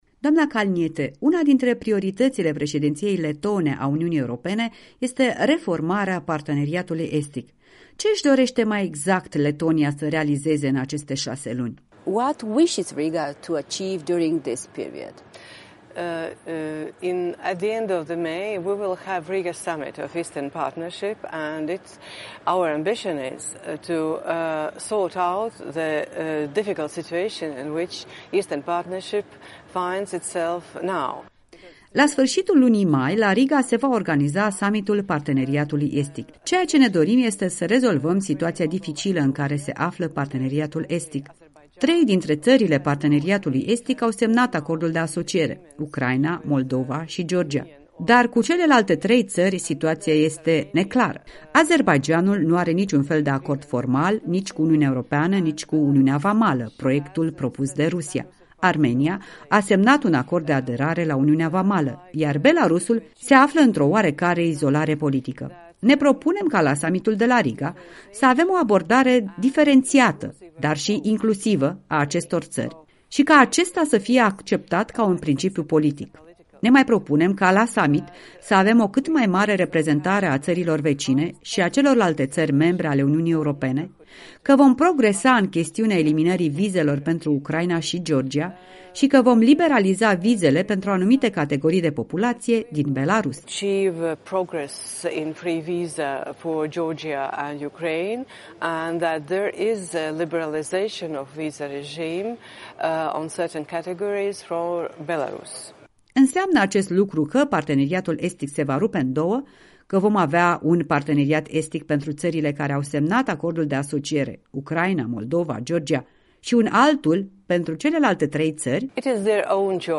În direct de la Strasbourg cu europarlamentara Sandra Kalniete